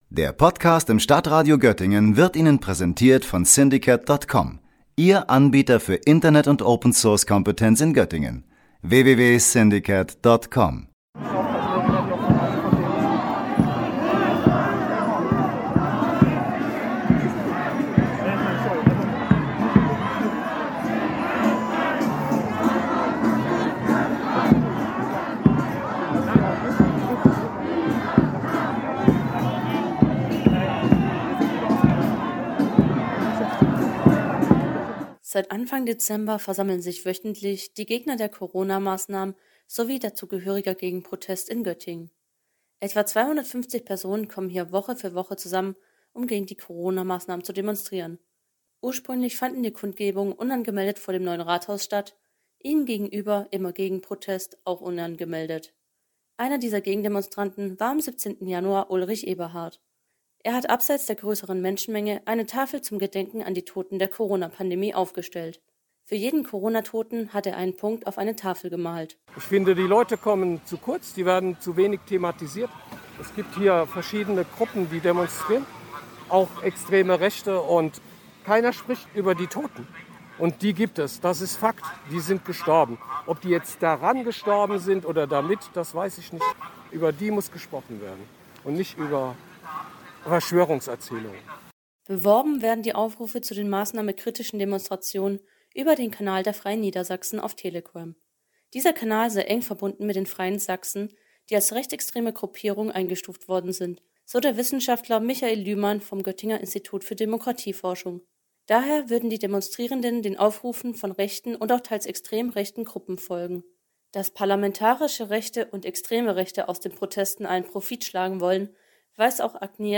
O-Ton 1, Atmo, 24 Sekunden
O-Ton 4, Atmo, 16 Sekunden
O-Ton 7, Atmo, Gegenprotest, 15 Sekunden